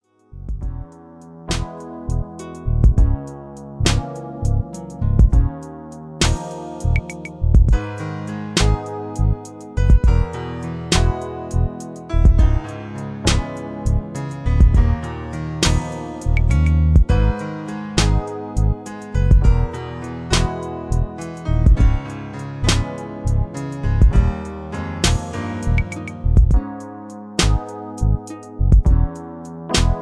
Gospel Vibe.